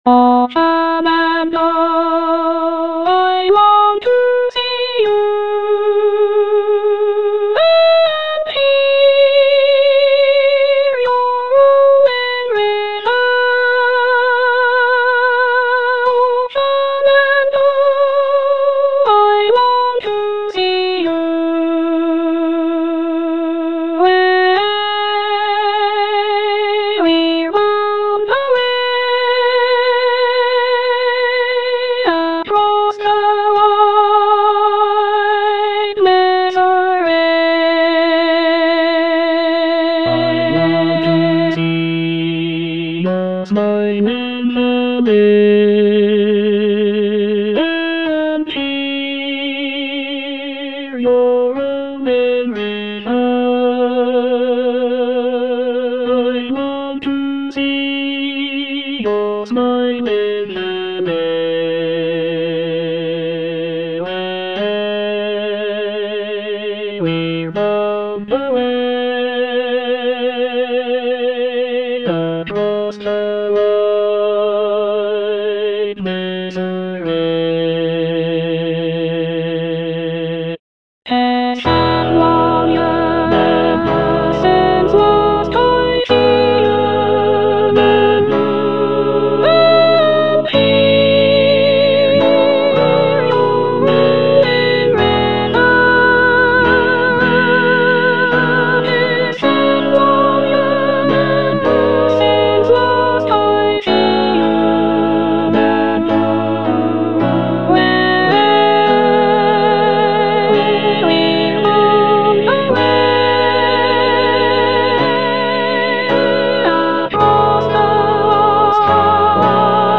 Soprano II (Emphasised voice and other voices)